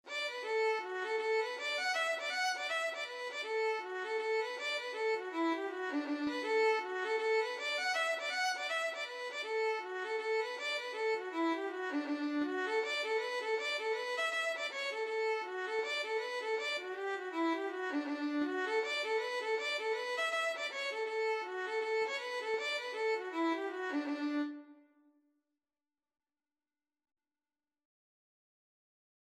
Free Sheet music for Violin
D major (Sounding Pitch) (View more D major Music for Violin )
4/4 (View more 4/4 Music)
Violin  (View more Intermediate Violin Music)
Traditional (View more Traditional Violin Music)
Irish